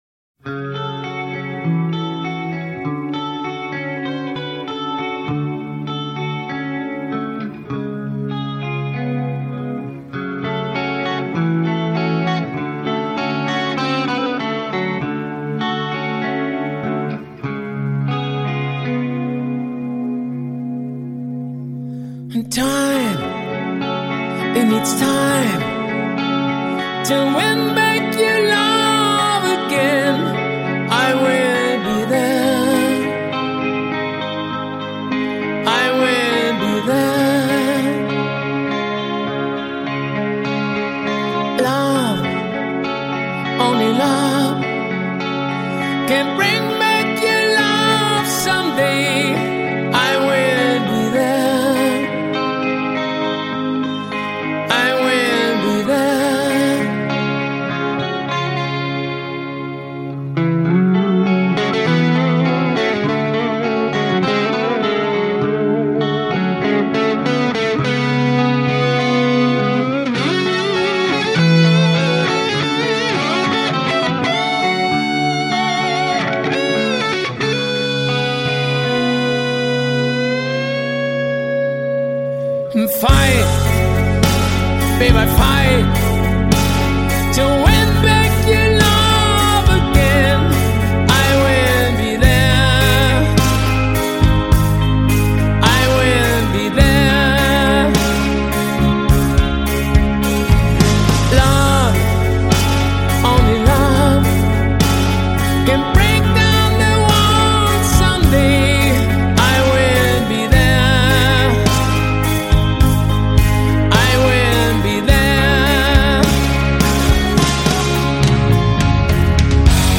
Жанр: Acoustic